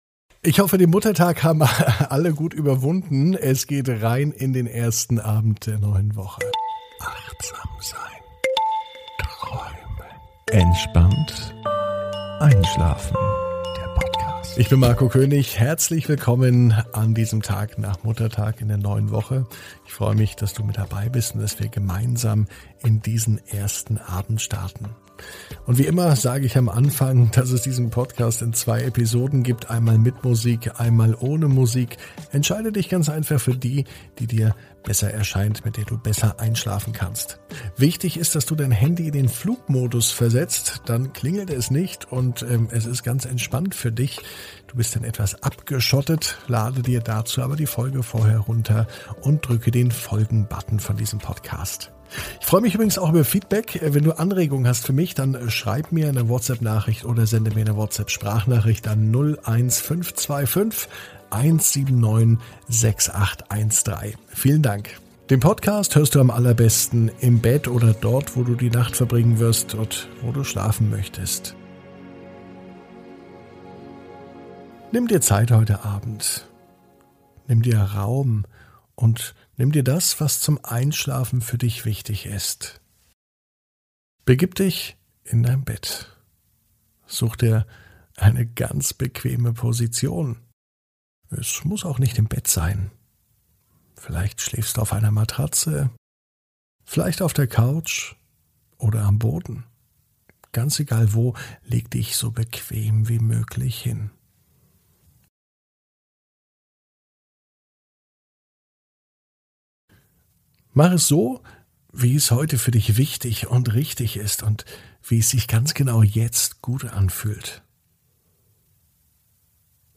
(ohne Musik) Entspannt einschlafen am Montag, 10.05.21 ~ Entspannt einschlafen - Meditation & Achtsamkeit für die Nacht Podcast